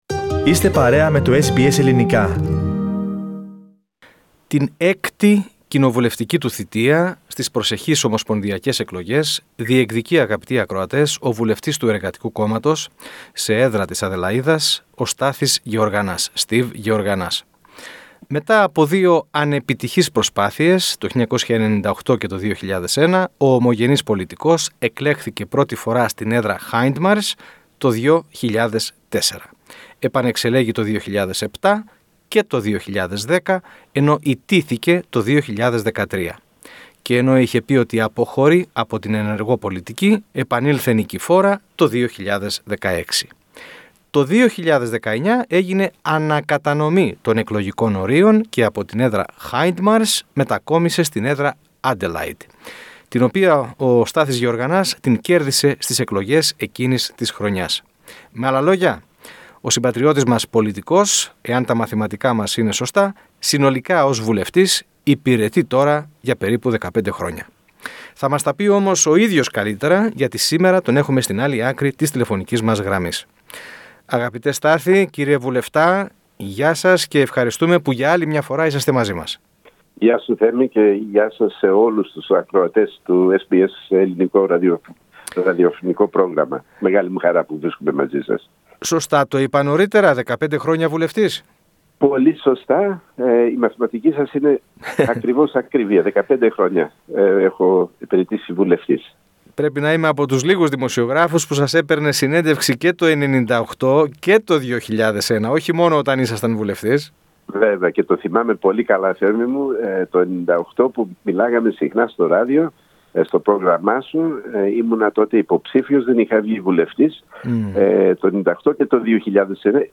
Ακούστε ολόκληρη τη συνέντευξη στο podcast.